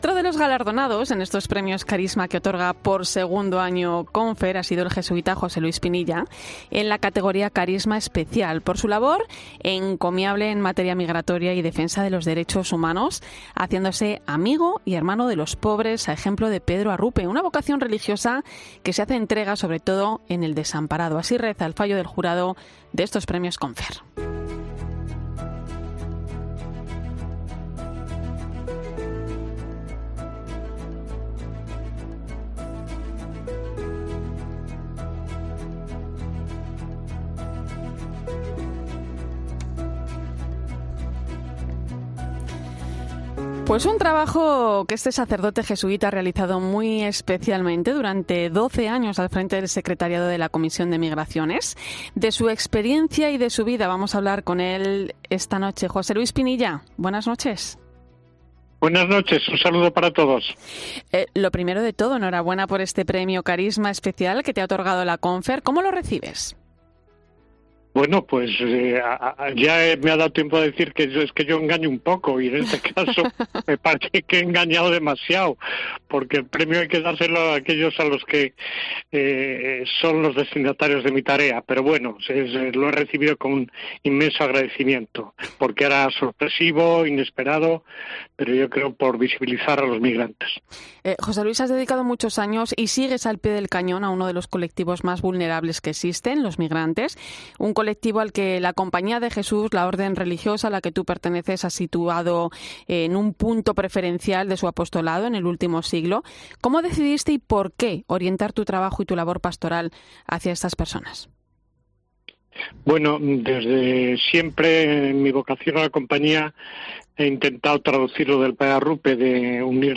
El jesuita reconoce, entre risas, que él engaña "un poco y, en esta ocasión, he engañado demasiado, porque el premio hay que dárselo a los destinatarios de mi tarea, pero bueno, lo recibo con inmenso agradecimiento porque es inesperado y porque visibiliza a los migrantes”.